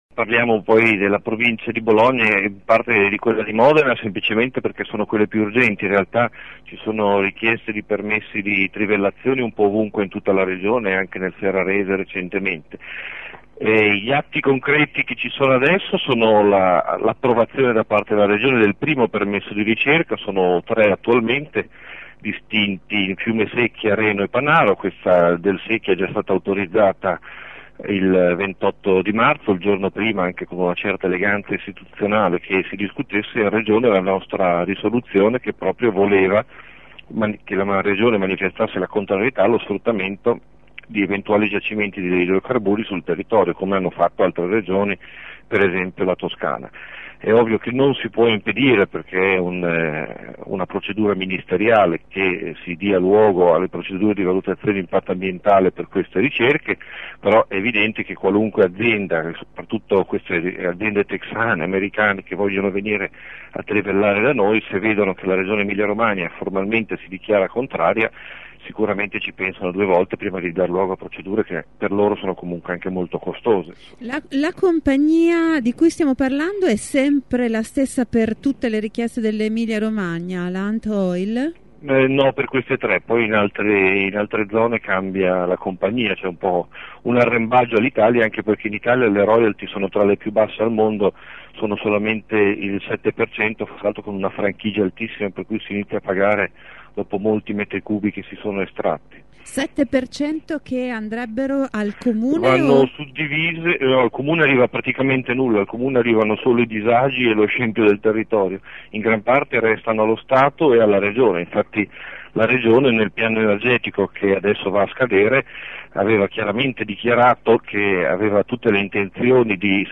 Abbiamo intervistato Andrea Defranceschi, consigliere 5Stelle: